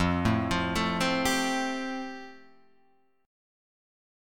F Major Add 9th